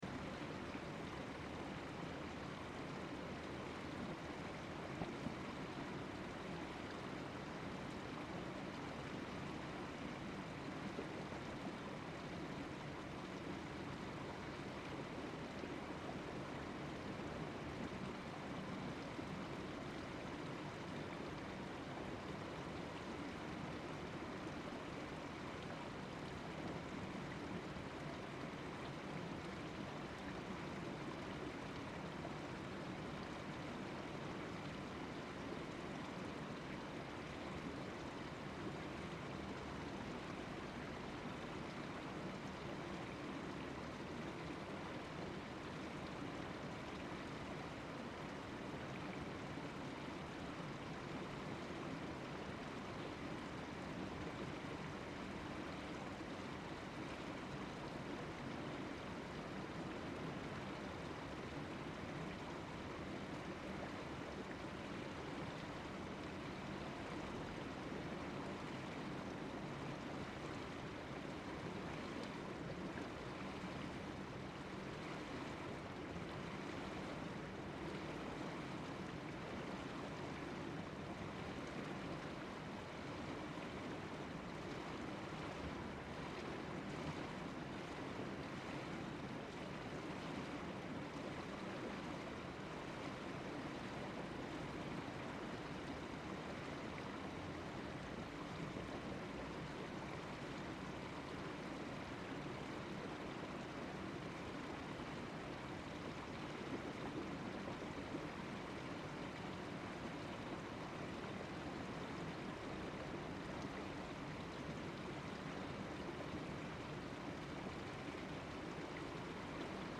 Тихий звук у пустого бассейна